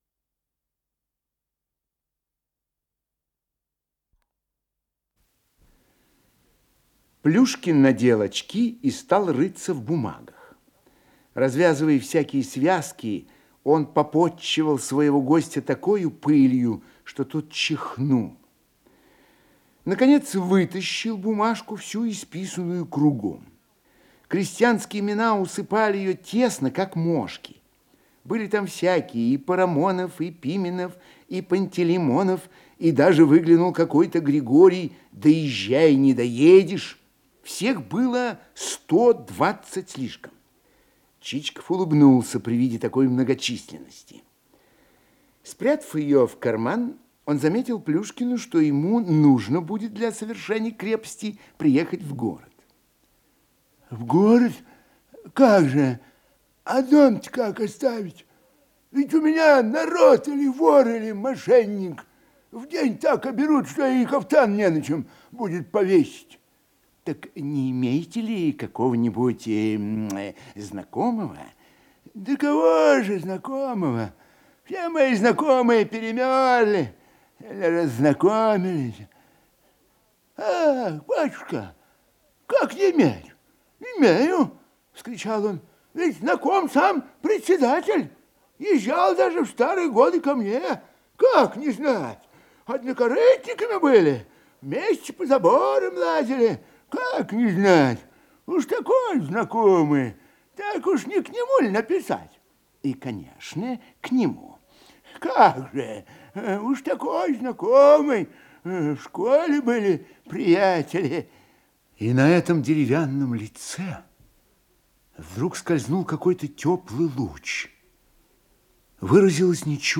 Исполнитель: Михаил Ульянов - чтение